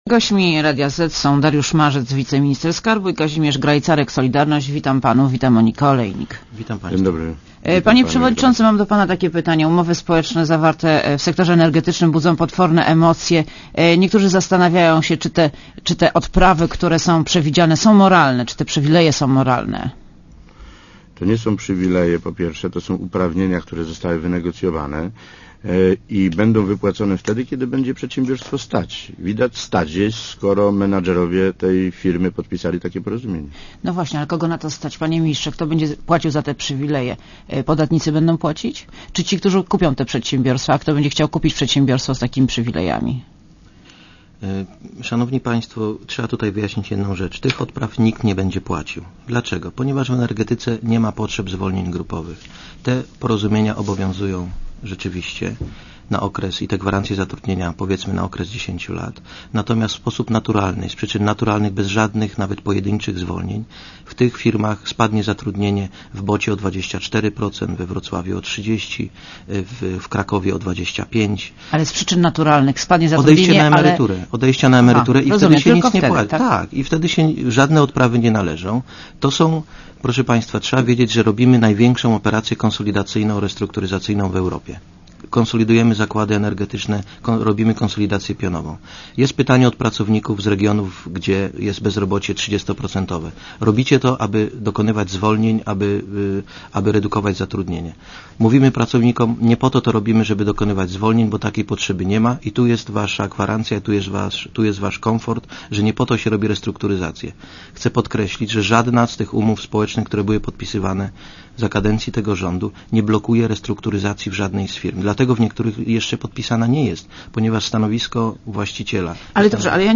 Posłuchaj wywiadu Gośćmi Radia Zet są Dariusz Marzec